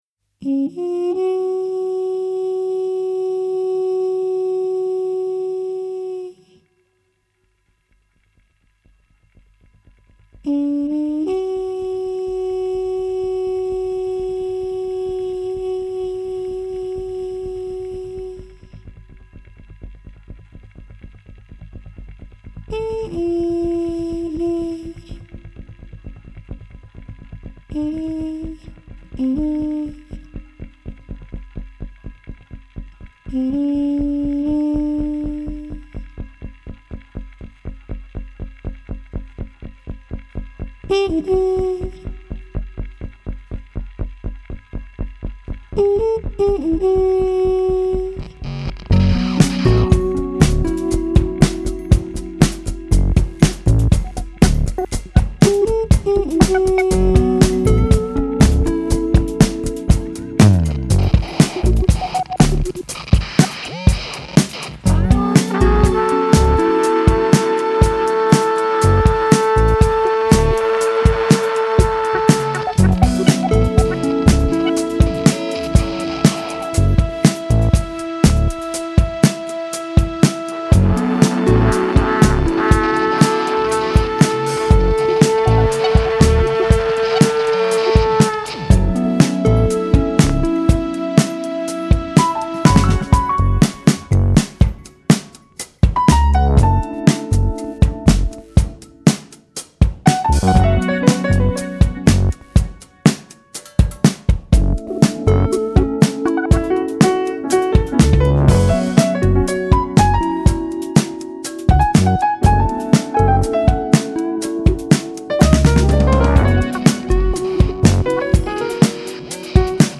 フィンランド発、スタイリッシュなクラブジャズサウンドが展開
keyboards, piano
trumpet, flugelhorn
double bass, bass guitar
drums
percussions